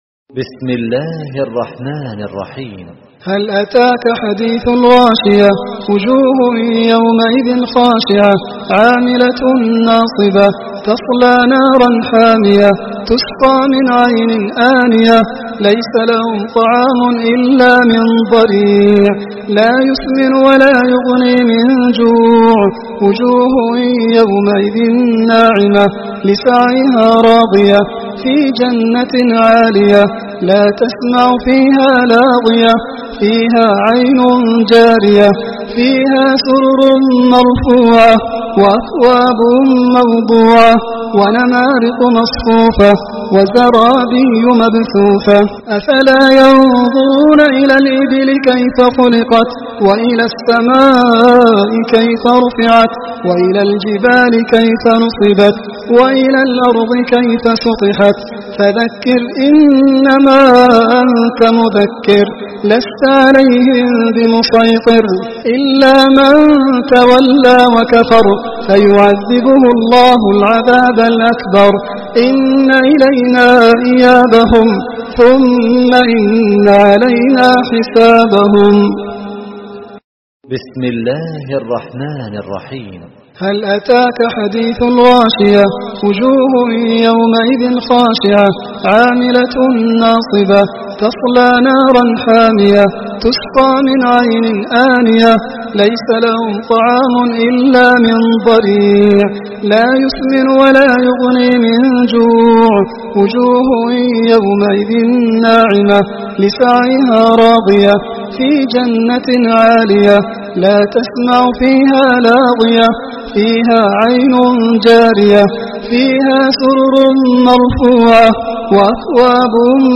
الغاشية تلاوة سورة الغاشية
رواية : حفص عن عاصم